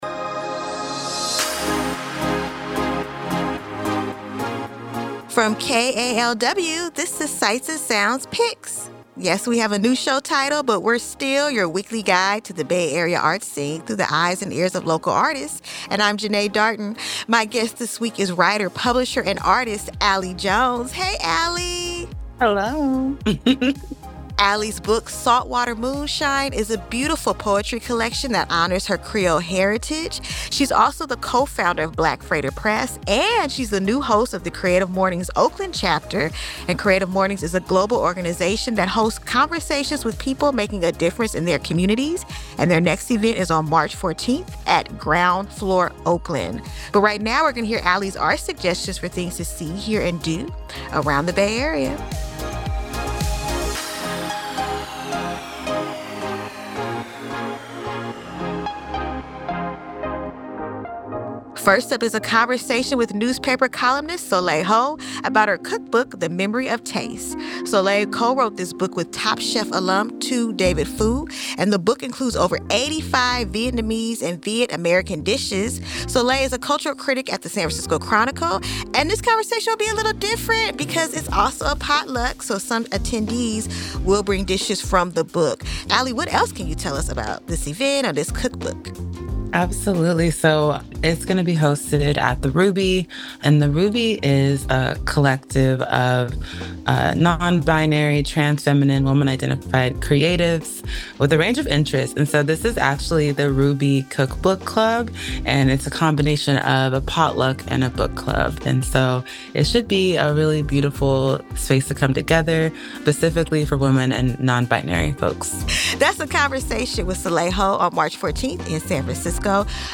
speaks with a different local artist about upcoming arts events in the Bay Area.